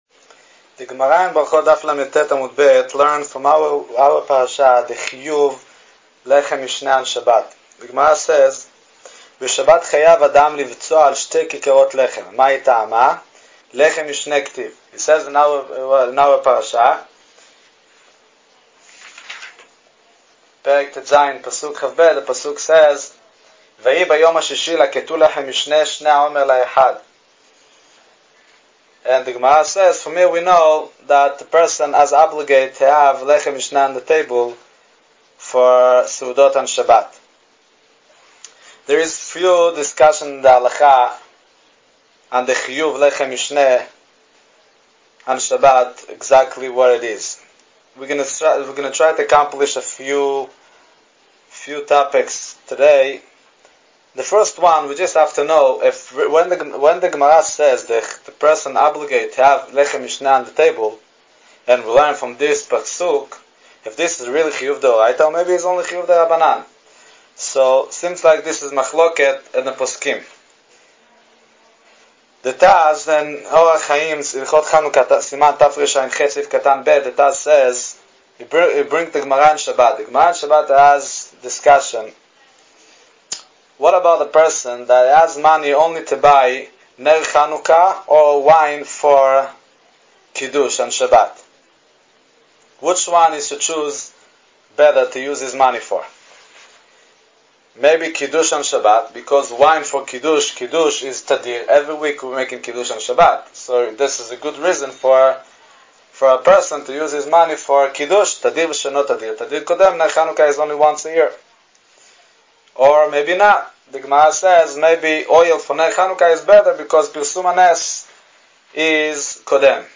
A Parasha & Halacha Audio Shiur on the Laws of Lehem Mishne, for Parashat Beshalah 5777